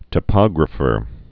(tə-pŏgrə-fər)